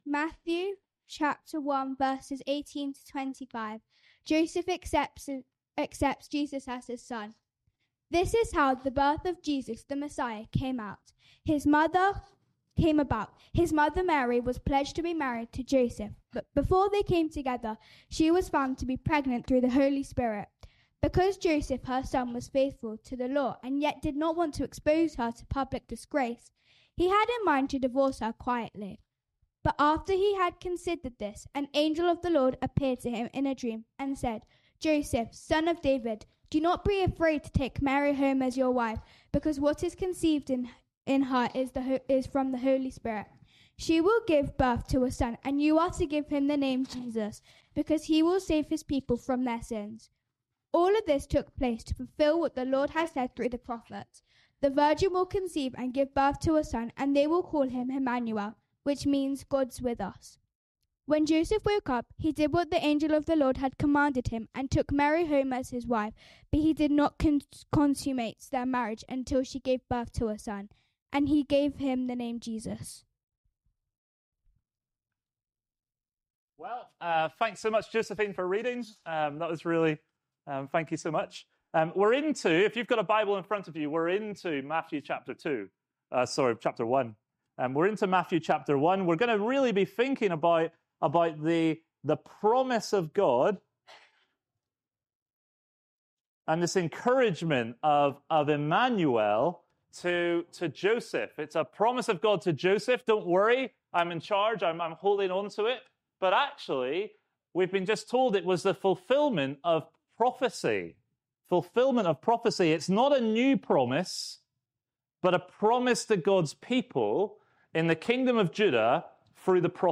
All Age Carol Service